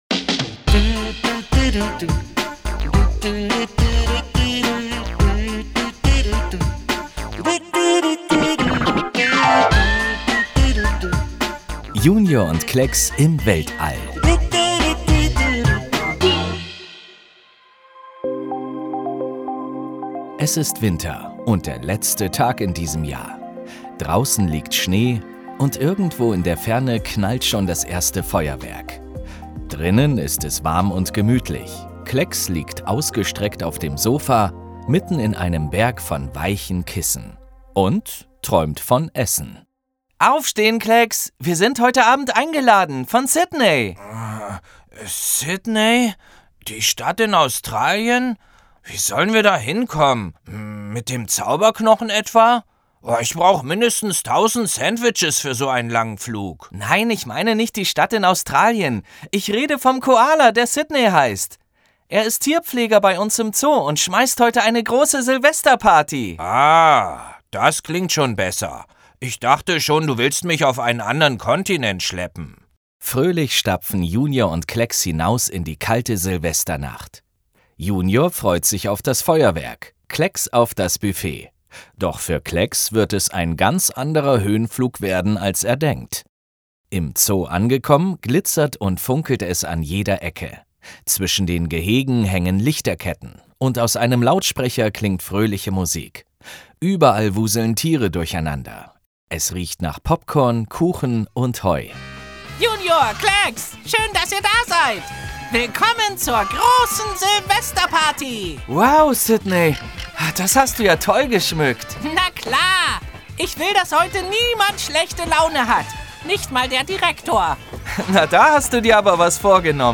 26 01 Hörspiel - JUNIOR Deutschland